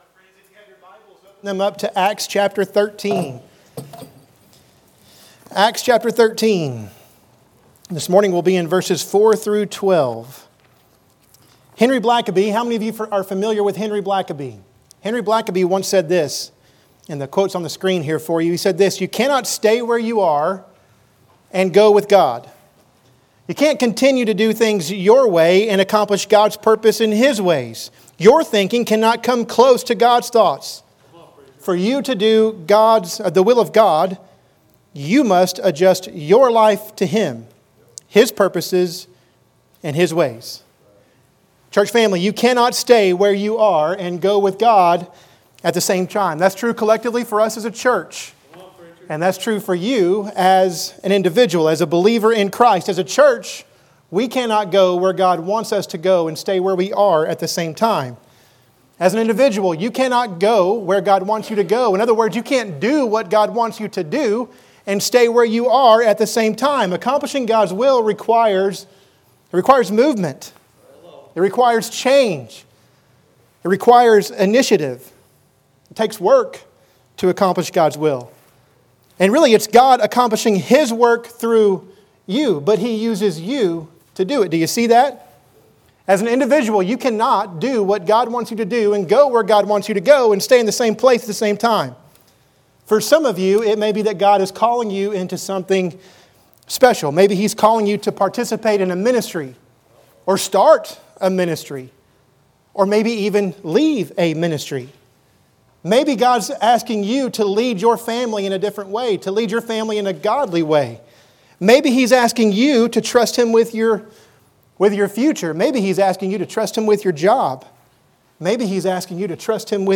In this sermon you'll discover three requirements for accomplishing God's will in your life. Open your Bibles to Acts 13:4-12 and follow along as we consider The Mission of a Sending Church.